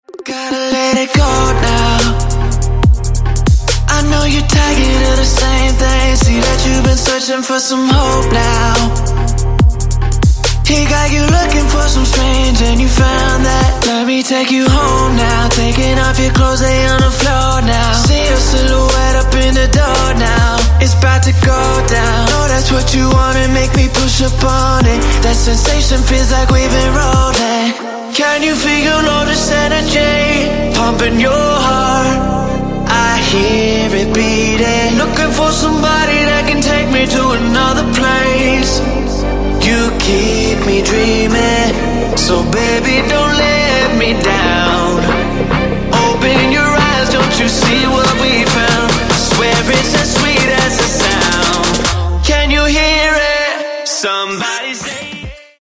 • Качество: 128, Stereo
поп
мужской вокал
dance
EDM
romantic
vocal